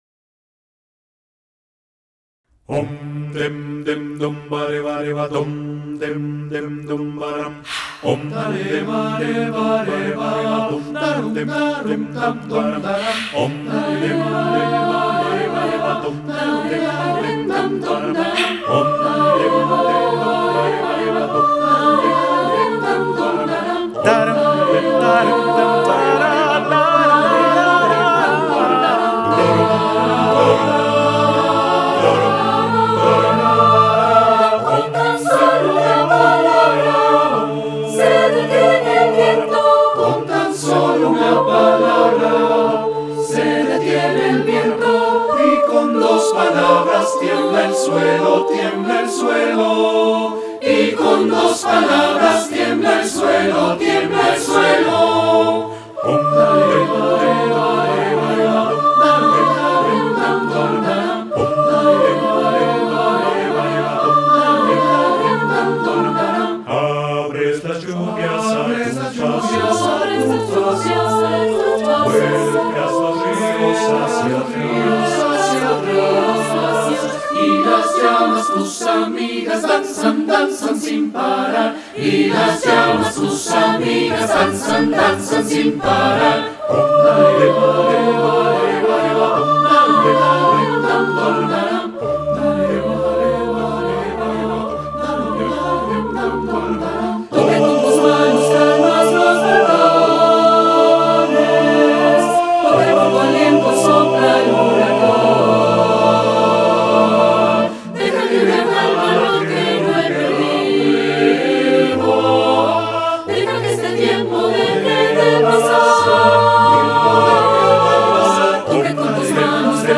Explore a musical world of supernatural wonder and power.
for SATB div. a cappella choir with tenor solo